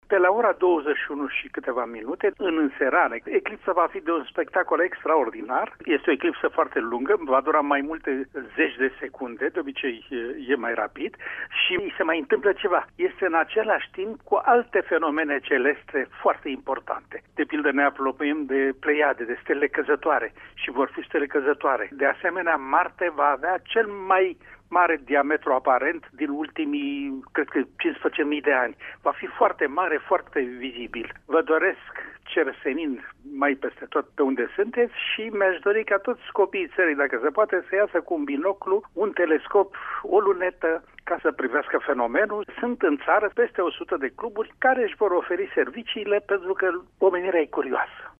Scriitorul şi jurnalistul Alexandru Mironov ne aduce mai multe informaţii despre aceste fenomene: